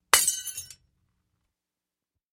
Звук разбившихся песочных часов